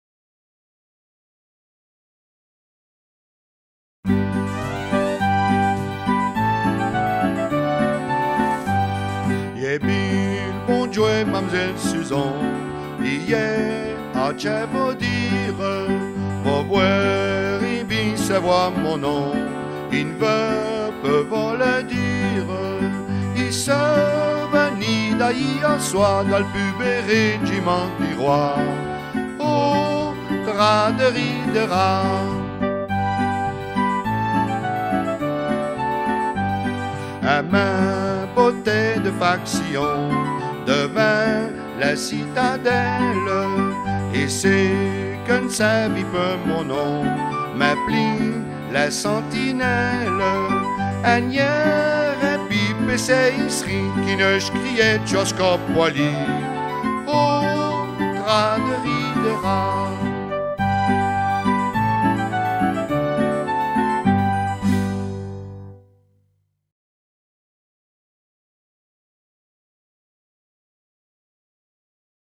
Suzon, paroles et musique